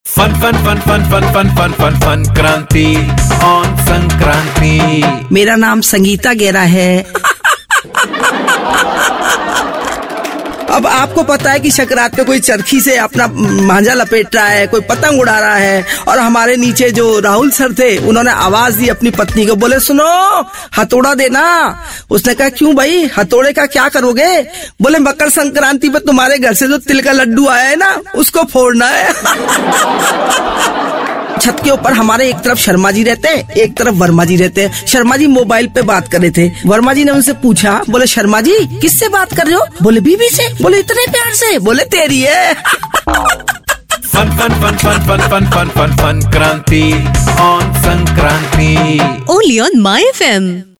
स्टेंडिंग कॉमेडियन